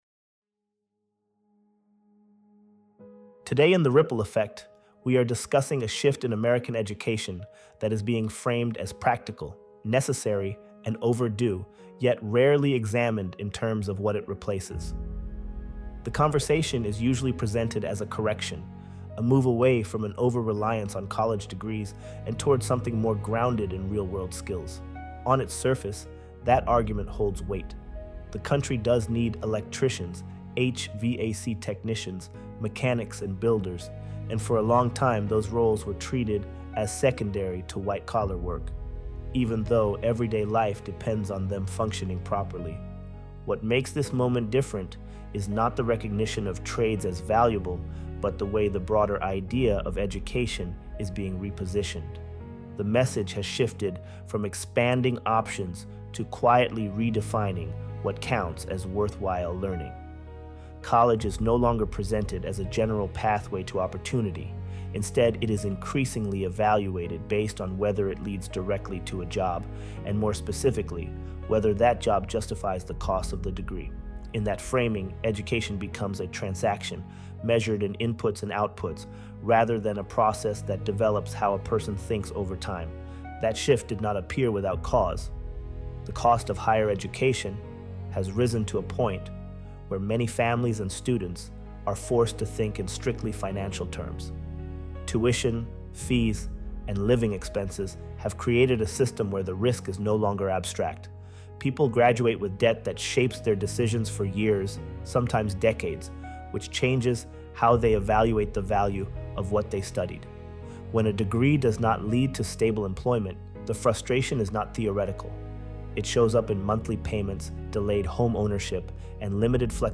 ElevenLabs_Education_vs_Training_Shift.mp3